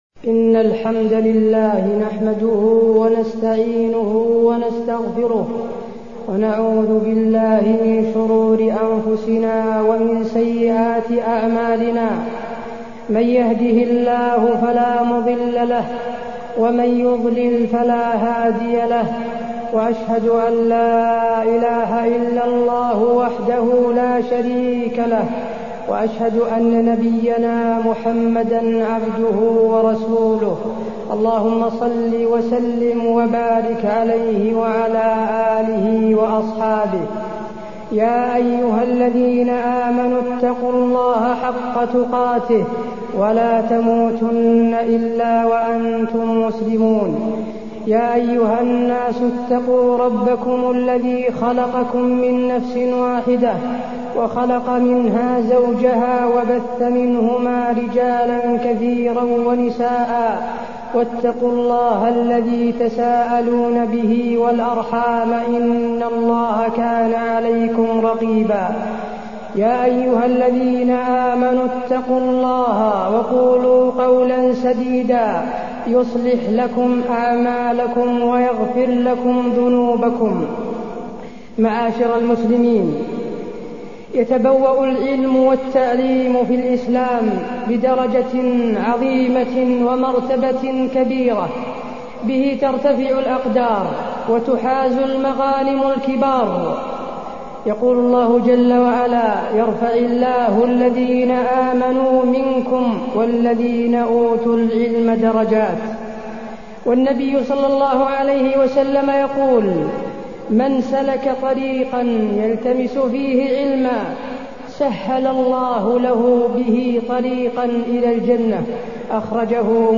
تاريخ النشر ١٢ جمادى الآخرة ١٤٢٢ هـ المكان: المسجد النبوي الشيخ: فضيلة الشيخ د. حسين بن عبدالعزيز آل الشيخ فضيلة الشيخ د. حسين بن عبدالعزيز آل الشيخ العلم والتعلم